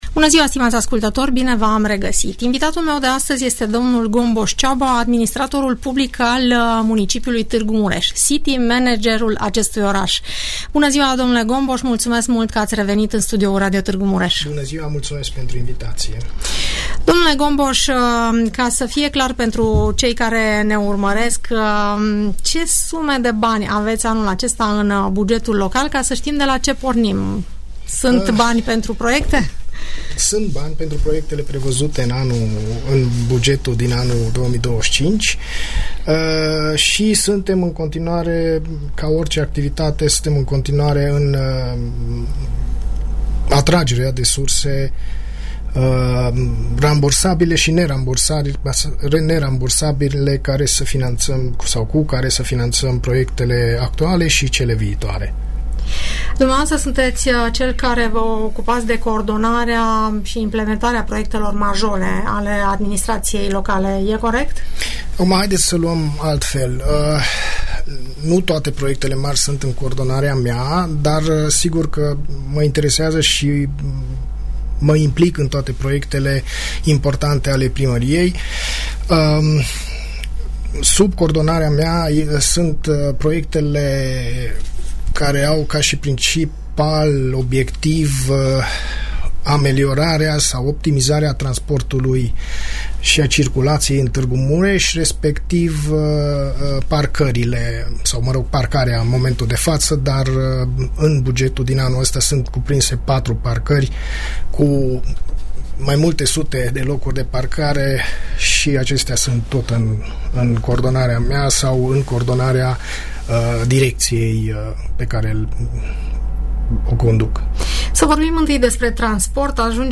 Care sunt cele mai importante activități aflate în derulare la Tg. Mureș și la ce proiecte de viitor se lucrează, aflăm de la city managerul municipiului dl Gombos Csaba, invitat în emisiunea "Părerea ta" . Urmărește dialogul moderat la Radio Tg. Mureș